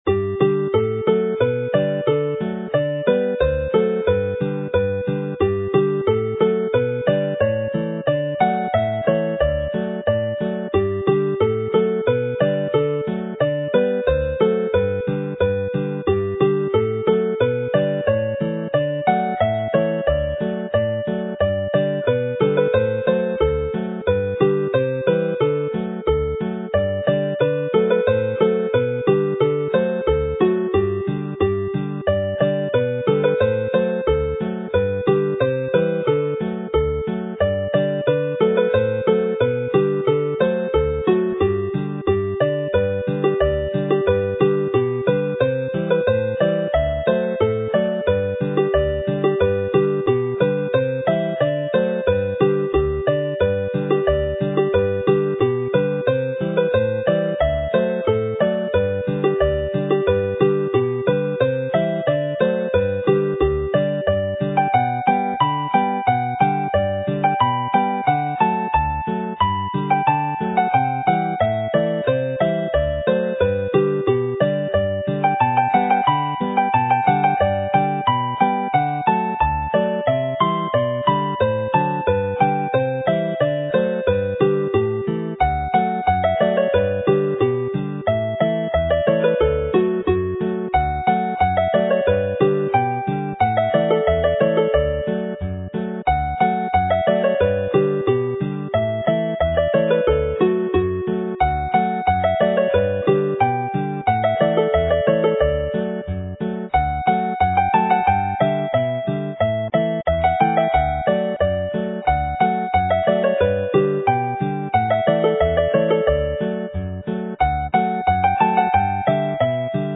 Alawon Cymreig - Set Joio / Having fun - Welsh folk tunes to play
Dyma set hwylus iawn sy'n canu'n dda fel polcas.
This jolly set which runs well as a set of polkas starts with the traditional dance Welsh Quack which dates back over 200 years and Rîl Llandaf is another traditional dance tune but the last tune is a new one.